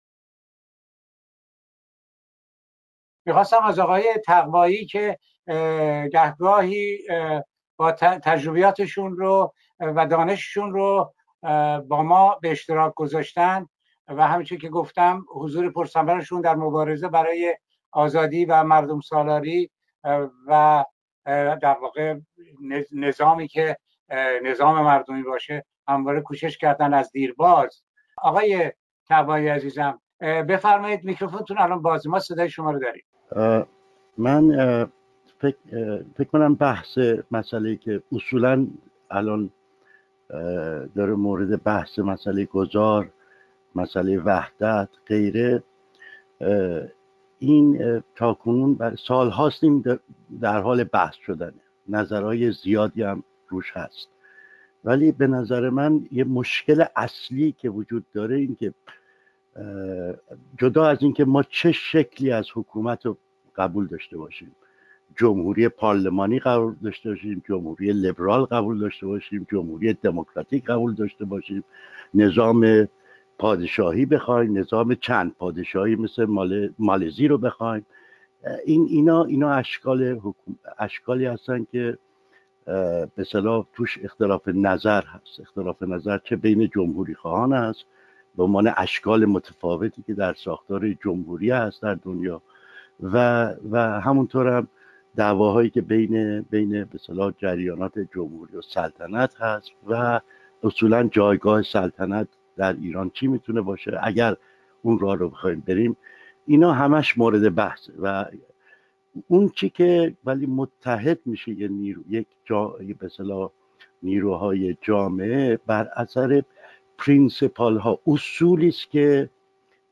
به گزارش گذار (سامانه دموکراسی و داد)، وبینار سراسری گذار از استبداد اسلامی به دموکراسی، با موضوع راهکارهای میدانی برای گذار خشونت پرهیز از استبداد اسلامی به دموکراسی در جهت حمایت و شرکت فعال ایرانیان خارج از کشور برای تغییرات بنیادی در حاکمیت سیاسی، با حضور جمعی از کنشگران مدنی، صاحب‌نظران و فعالان سیاسی در روز یک‌شنبه ۱۰ ژوئن ۲۰۱۸ (۲۰ خردادماه ۱۳۹۷)، برگزار شد.